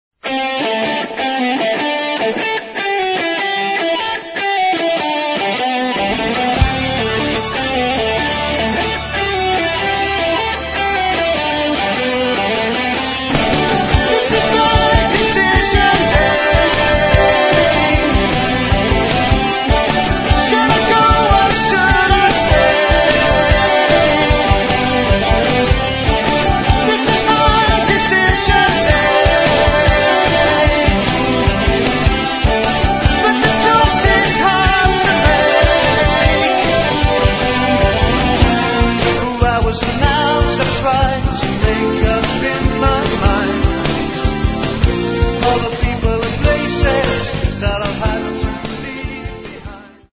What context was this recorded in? Diploma Studio, Malden, Essex 1990.